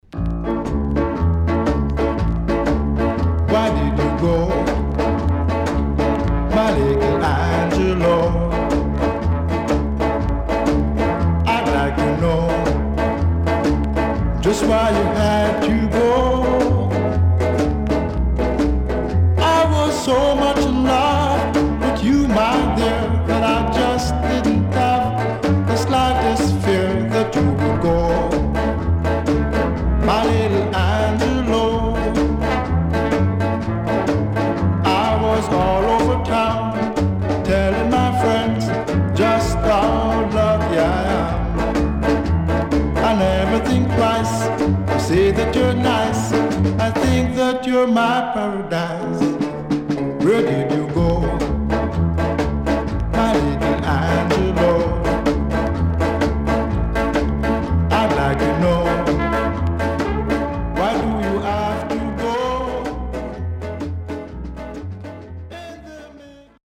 HOME > Back Order [VINTAGE 7inch]  >  EARLY 60’s  >  SHUFFLE
W-Side Good Shuffle
SIDE A:所々チリノイズがあり、少しプチノイズ入ります。